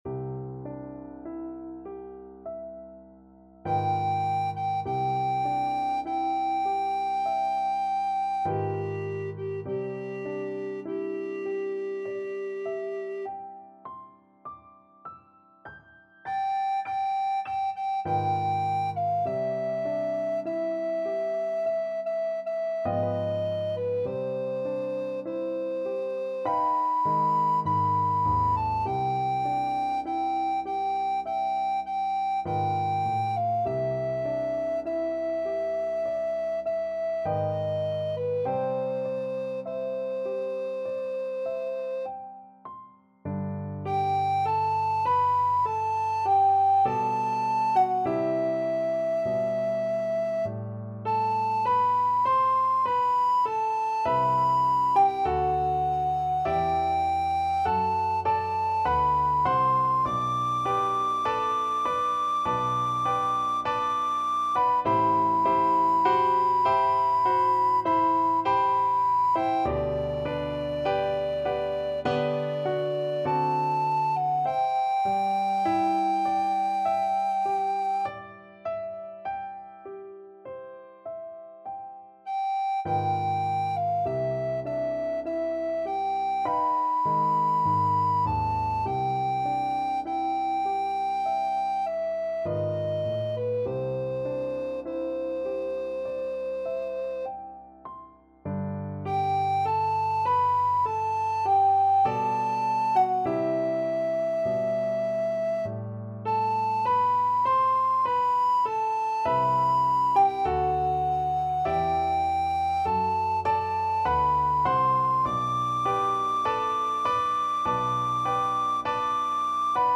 4/4 (View more 4/4 Music)
Classical (View more Classical Alto Recorder Music)